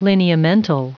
Prononciation du mot lineamental en anglais (fichier audio)
Prononciation du mot : lineamental